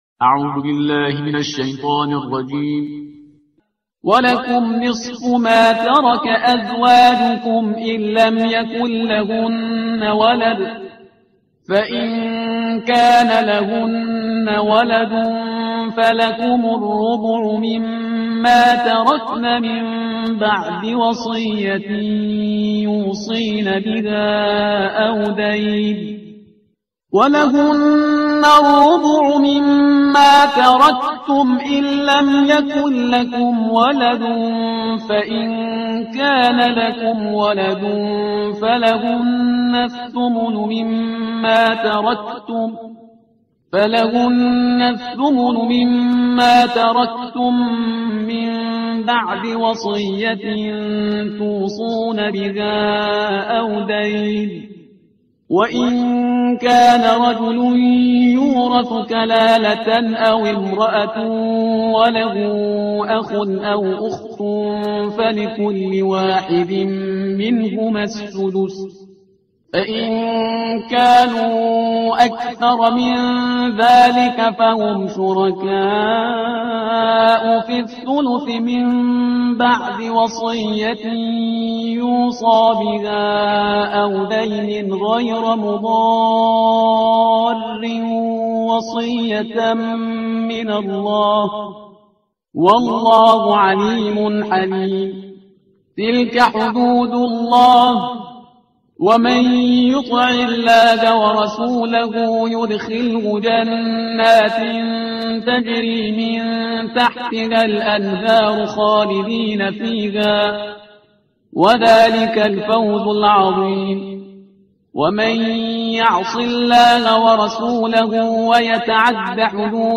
ترتیل صفحه 79 قرآن – جزء چهارم